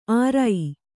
♪ ārai